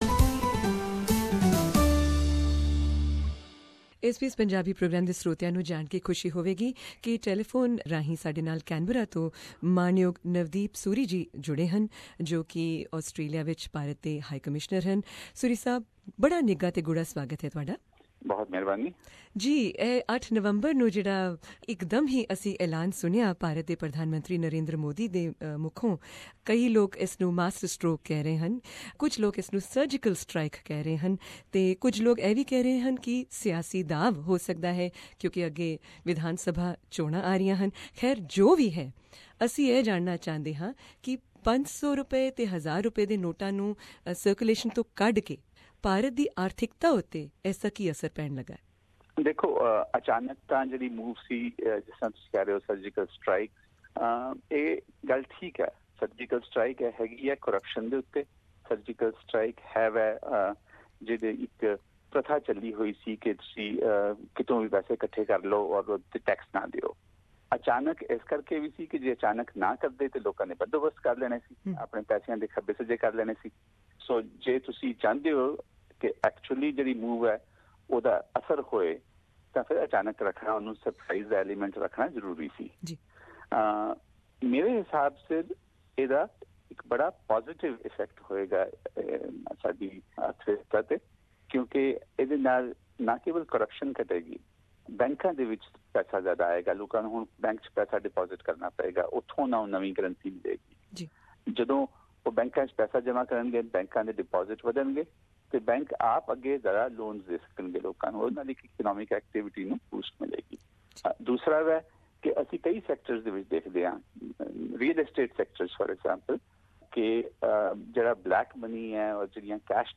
We also asked him about the effect of this "surgical strike" on the overall Indian economy, and its possible effect on upcoming state elections in India, including Punjab. Hear Mr Suri's answers, in this interview...